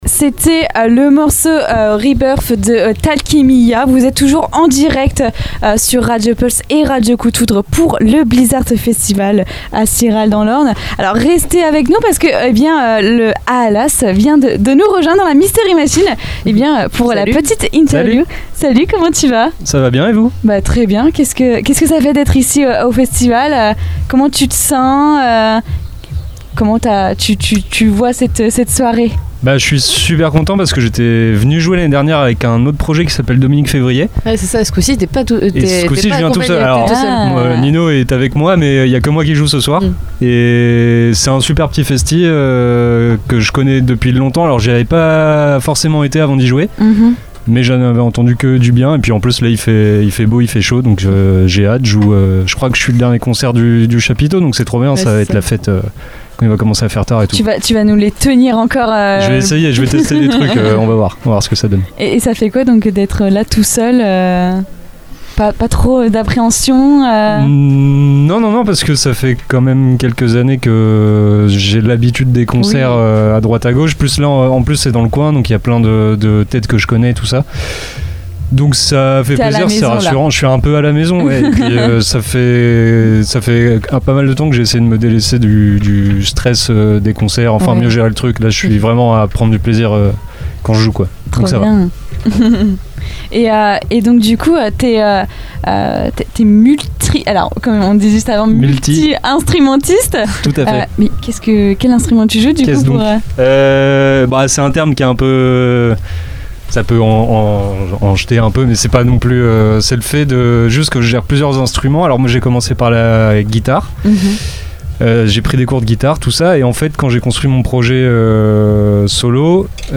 Quelques instants après leur concert, nous avons retrouvé le groupe dans la Mystery Machine (studio radio aménagé dans une camionnette) pour une interview exclusive diffusée en direct sur Radio Pulse et Radio Coup de Foudre. Durant cet échange, les membres de Aalas nous racontent la genèse du projet, leur approche musicale entre post-metal, atmosphérique et influences extrêmes, mais aussi la place centrale qu’occupe l’émotion dans leur création. Ils partagent leurs sources d’inspiration, leur lien à la scène, et l’importance du son comme vecteur d’expériences profondes.